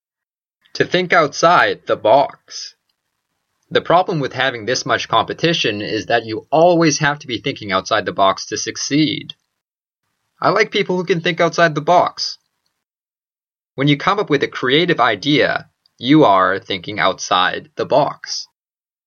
英語ネイティブによる発音は下記のリンクをクリックしてください。
tothinkoutsidethebox.mp3